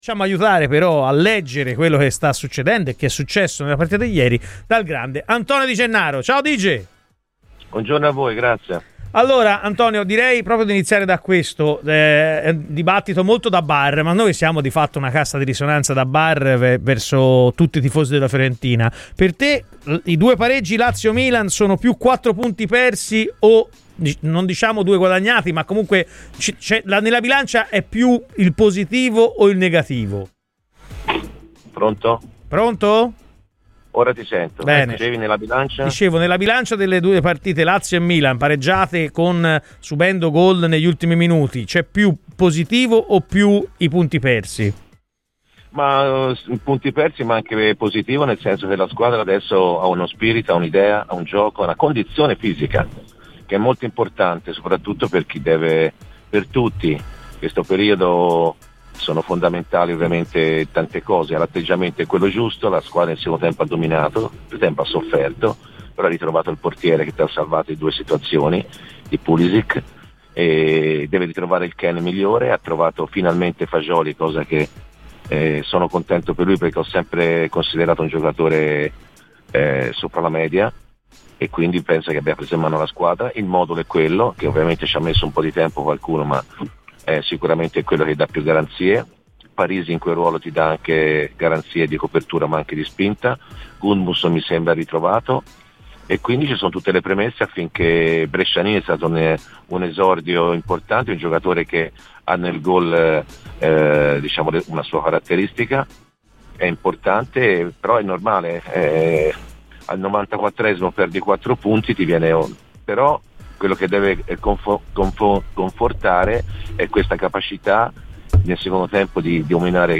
Antonio Di Gennaro, ex viola e opinionista per Radio FirenzeViola, ha parlato così in diretta sulle nostre frequenze - nel corso di "Chi si compra?" - dopo il pareggio della Fiorentina ottenuto contro il Milan: "La squadra adesso ha uno spirito, un'idea e una condizione fisica, che vale tanto. L'atteggiamento è corretto e nel secondo tempo la Fiorentina ha dominato. Deve ritrovare il Kean migliore ma ha trovato il miglior Fagioli, poi il modulo è quello che dà più garanzie e anche Gudmundsson mi pare ritrovato. Quel che conforta è la capacità di dominare il Milan nella ripresa, si vedono anche abbracci tra i giocatori ed è sintomo di un gruppo ritrovato".